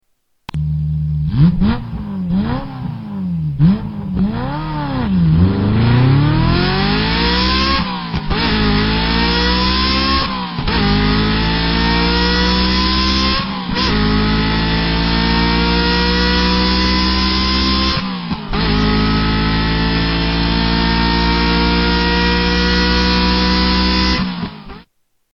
Ferrari F355